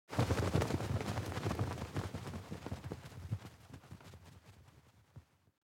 جلوه های صوتی
دانلود صدای پرنده 55 از ساعد نیوز با لینک مستقیم و کیفیت بالا